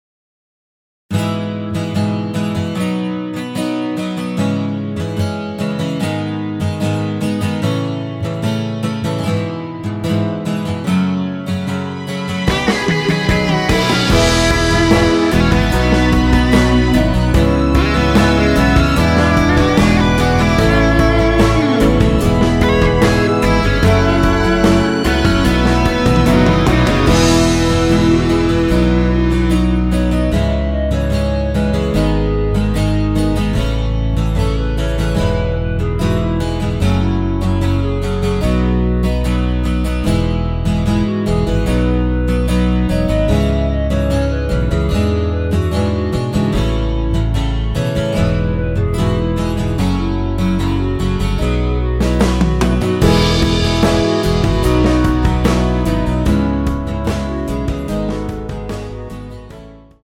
원키에서(-3)내린 멜로디 포함된 MR입니다.
멜로디 MR이라고 합니다.
앞부분30초, 뒷부분30초씩 편집해서 올려 드리고 있습니다.
중간에 음이 끈어지고 다시 나오는 이유는